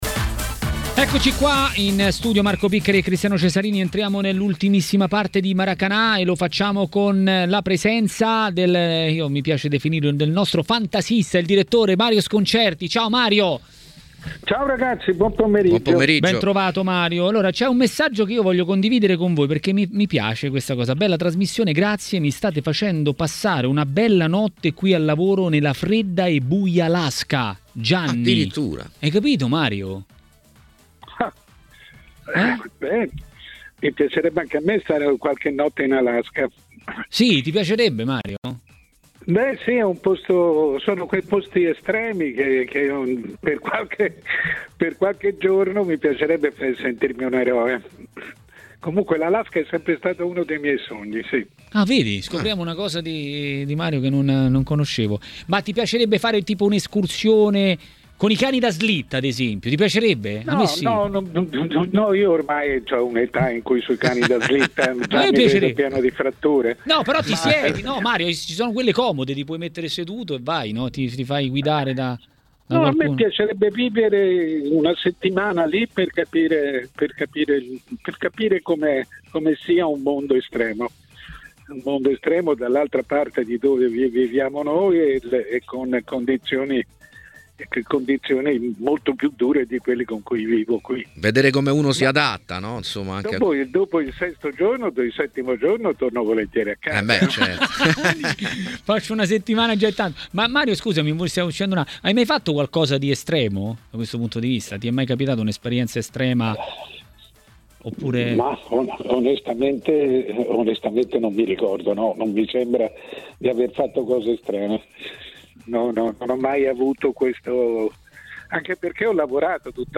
A Maracanà, nel pomeriggio di TMW Radio, è arrivato il momento del giornalista Mario Sconcerti.